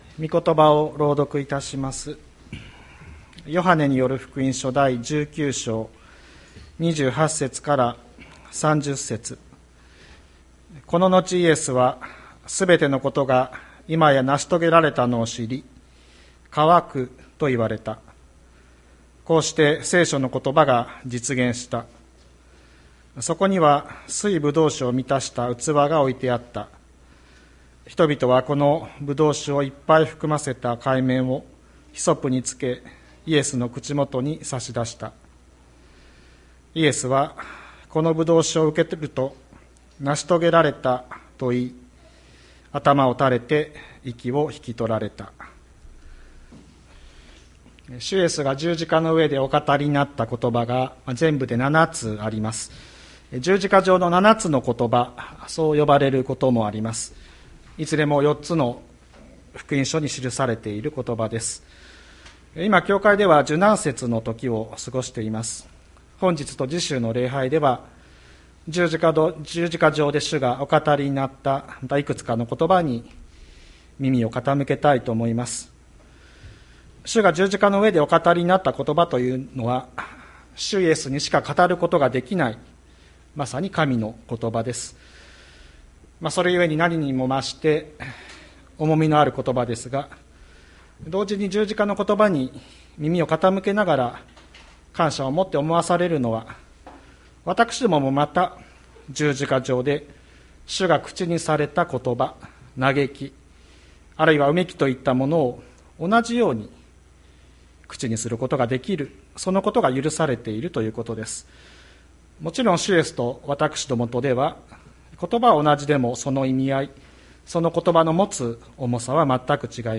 2022年04月03日朝の礼拝「主イエスの渇き」吹田市千里山のキリスト教会
千里山教会 2022年04月03日の礼拝メッセージ。